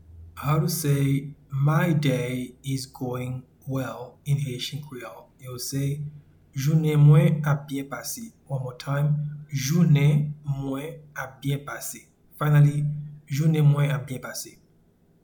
Pronunciation and Transcript:
My-day-is-going-well-in-Haitian-Creole-Jounen-mwen-ap-byen-pase.mp3